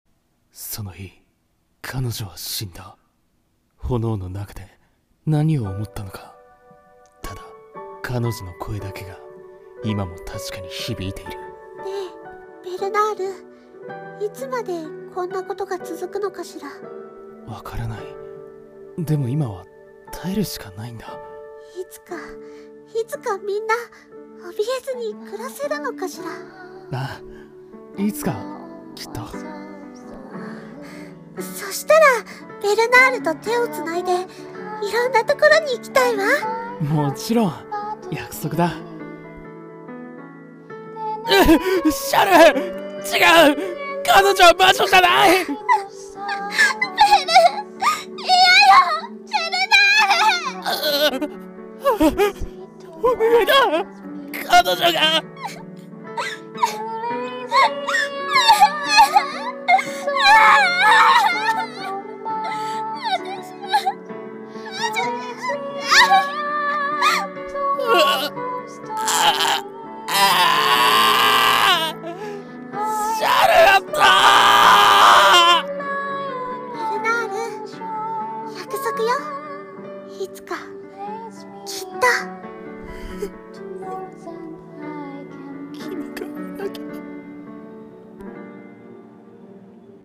声劇台本】魔女と呼ばれた君の